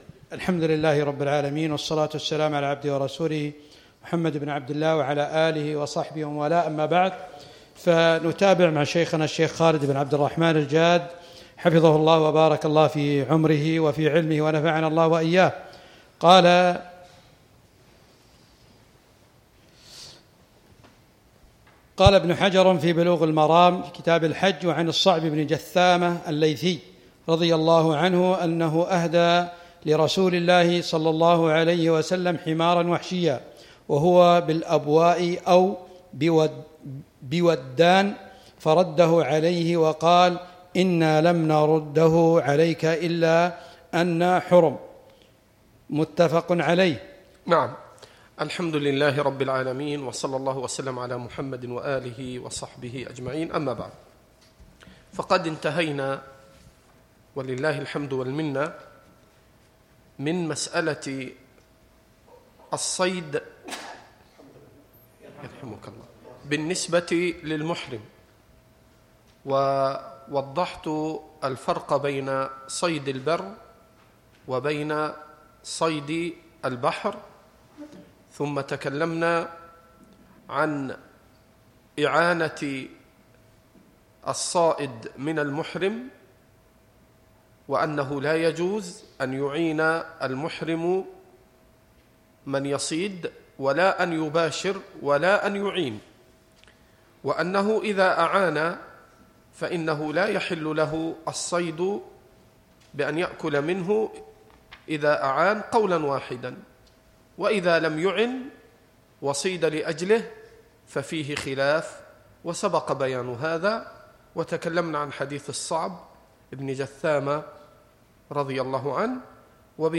الدرس التاسع - شرح كتاب الحج من بلوغ المرام